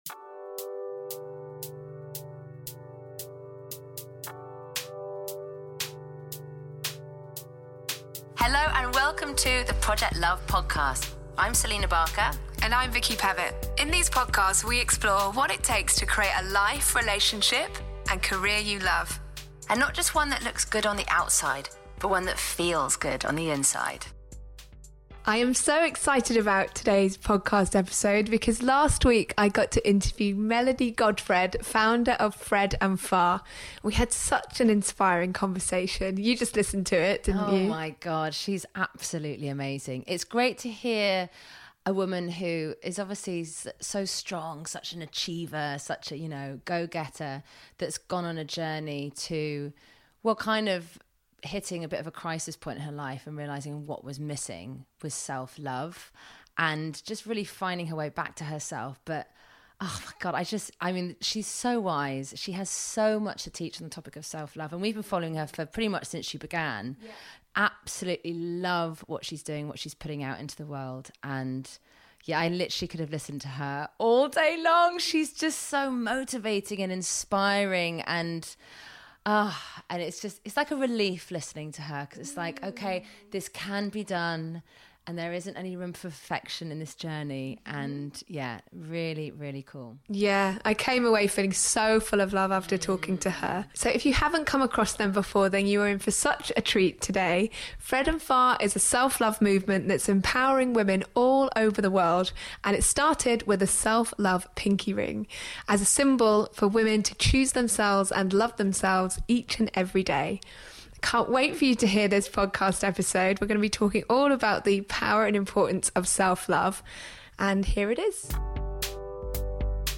EP 57: Committing to Self Love | Interview